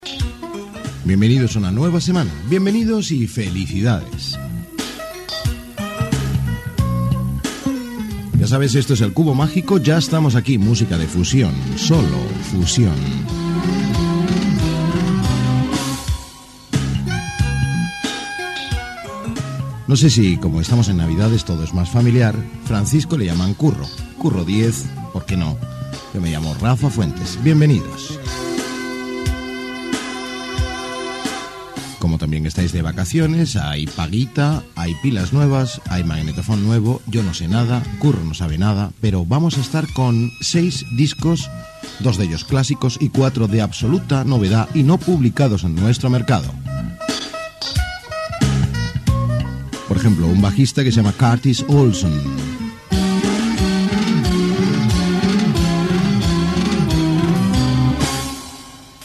Presentació del programa i del primer tema musical.
Musical